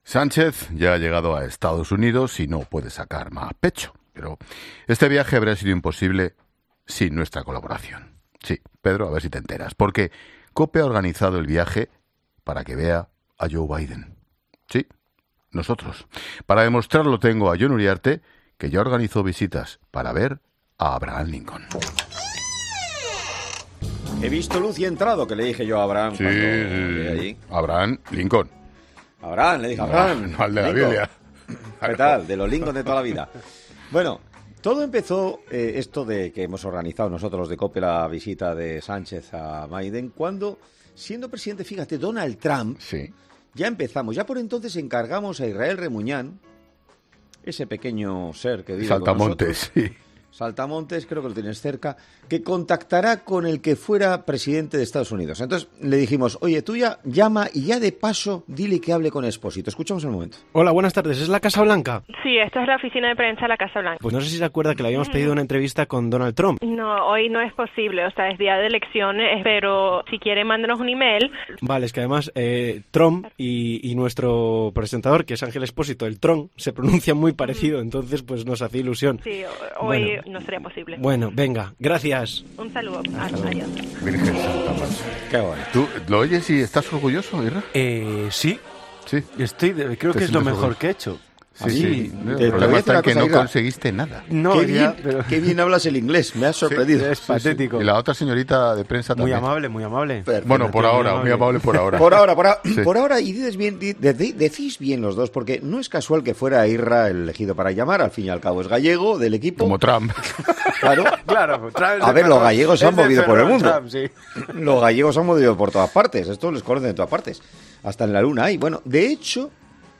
La llamada surrealista a la Casa Blanca con la que Expósito no puede contener la risa: My president es...